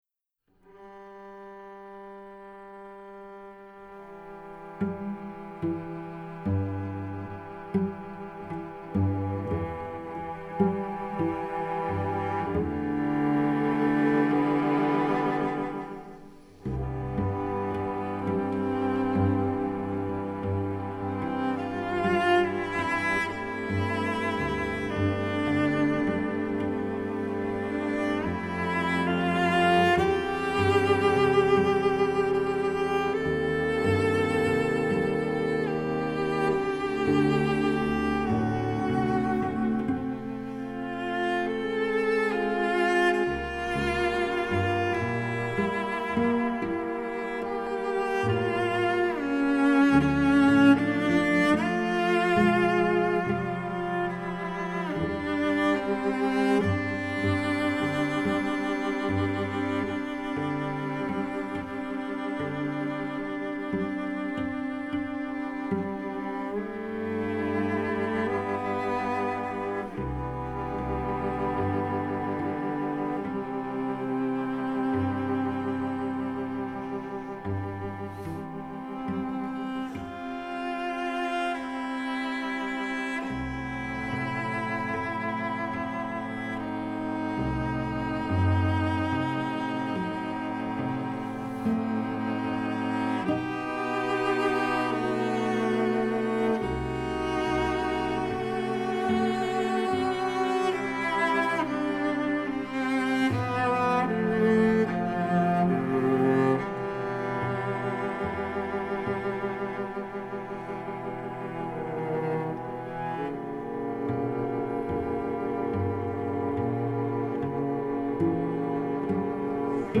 Voicing: 6 Cello